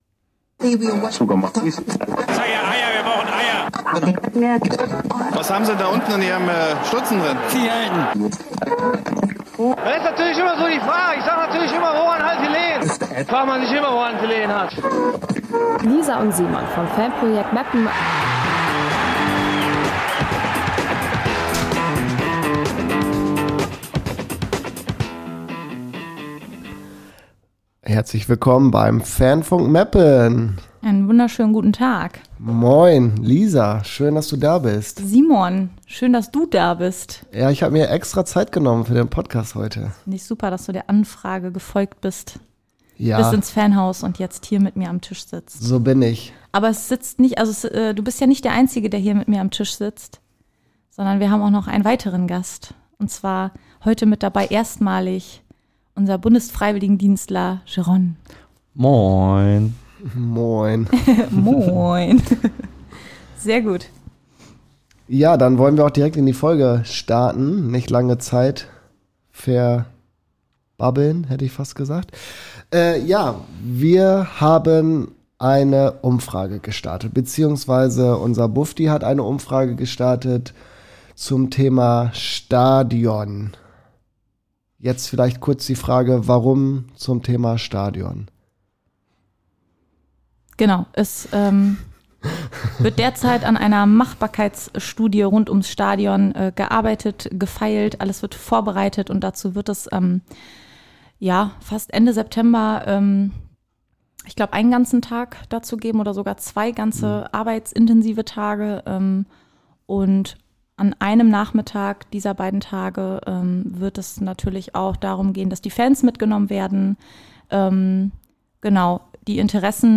Heute sprechen wir mal über unser Wohnzimmer, unser Emslandstadion. Lange diskutiert von Fans, Presse und Verein, soll nun eine Machbarkeitsstudie erstellt werden. Wir sprechen heute zu dritt über das Thema.